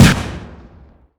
sol_reklam_link sag_reklam_link Warrock Oyun Dosyalar� Ana Sayfa > Sound > Weapons > Mp5 Dosya Ad� Boyutu Son D�zenleme ..
WR_fire.wav